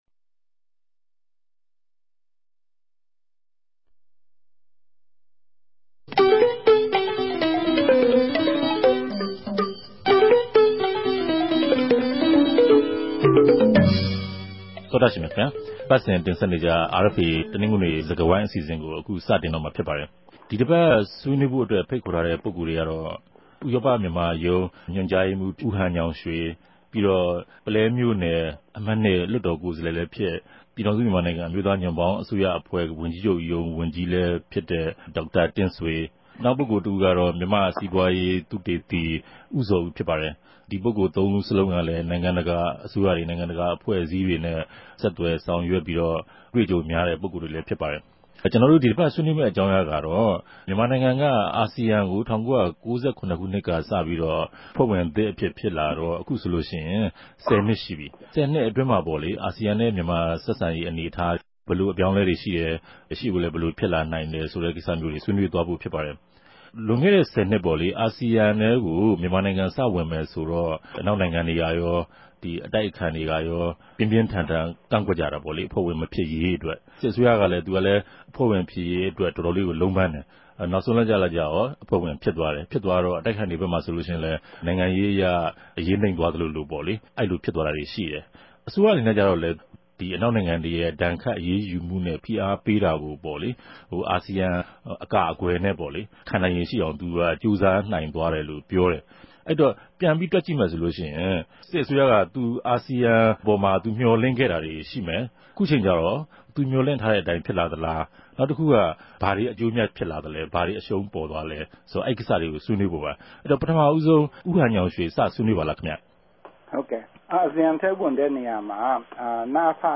တနဂဿေိံြ ဆြေးေိံြးပြဲစကားဝိုင်း